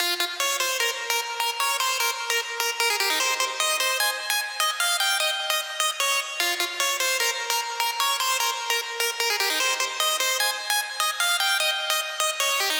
150BPM Lead 09 Fmin.wav